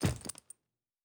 打开背包.wav